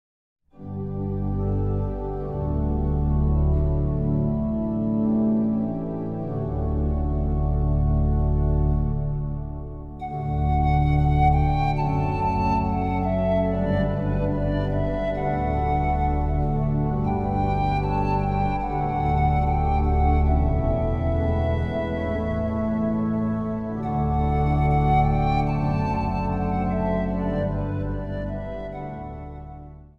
Zang | Jongerenkoor
De opname was in de Hervormde kerk van Hasselt.